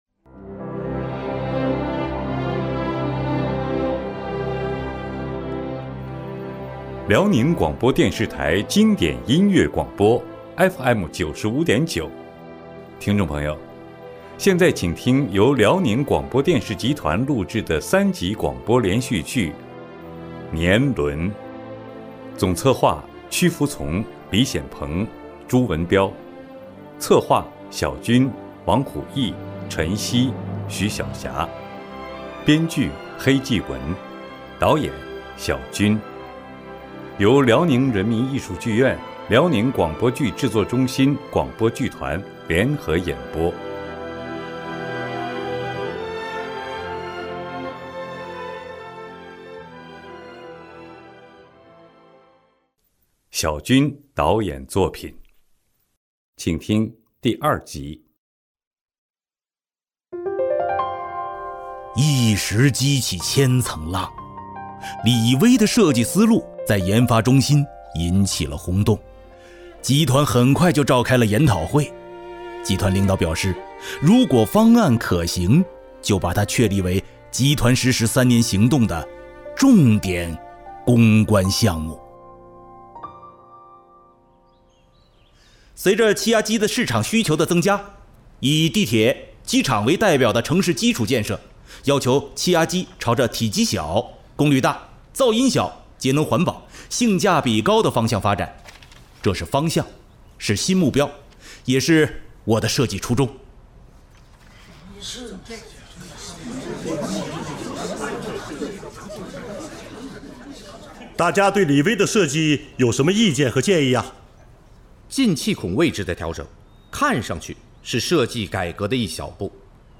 广播类型：连续剧